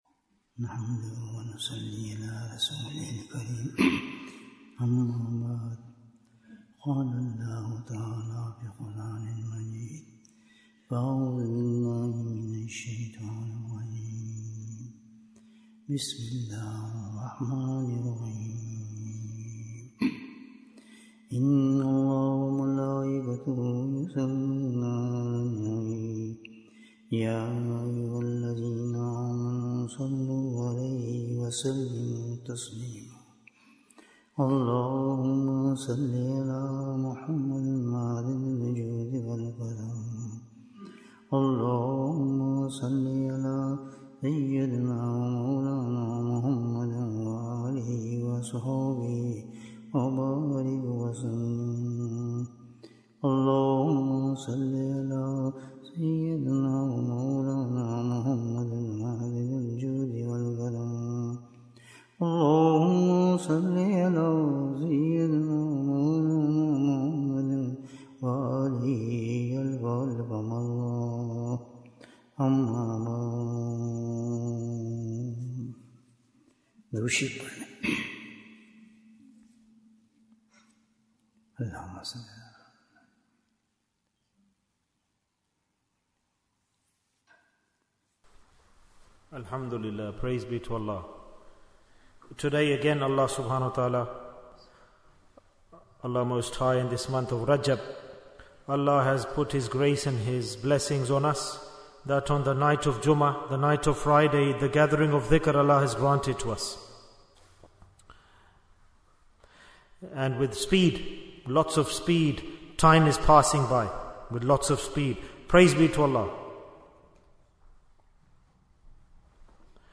Bayan, 90 minutes 16th January, 2025 Click for Urdu Download Audio Comments What is the Sign of a True Devout?